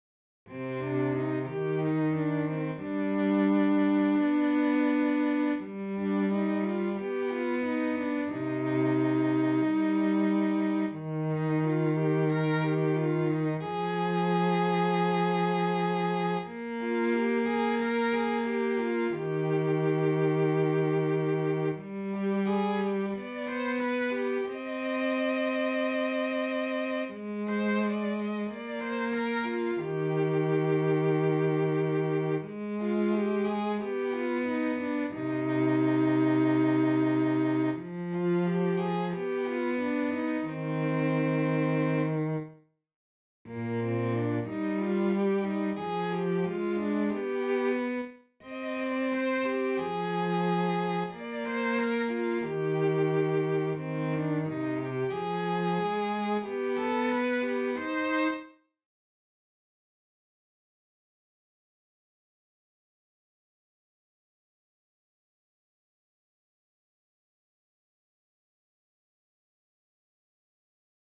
sirpaleiden-maassa-sellot.mp3